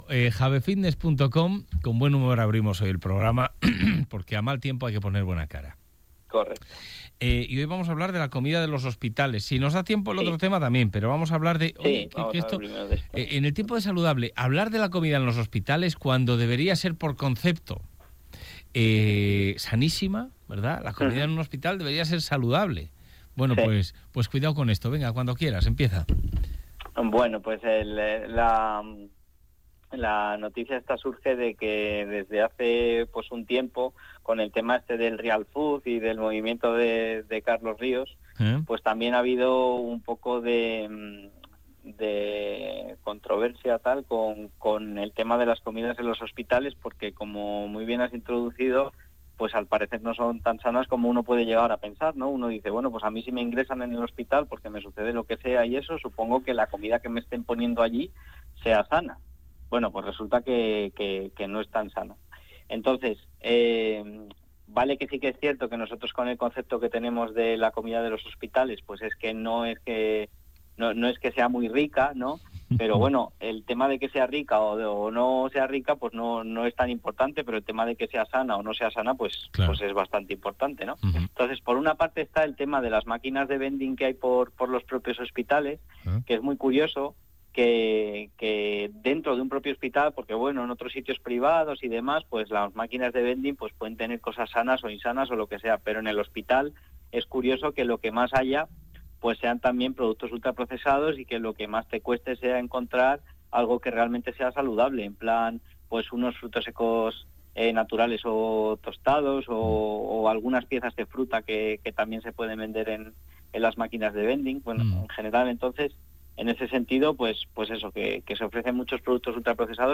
Hoy os traigo el centésimo nonagésimo cuarto programa de la sección que comenzamos en la radio local hace un tiempo  y que hemos denominado Es Saludable, en el programa Es la Mañana de León, Astorga y La Bañeza en EsRadio.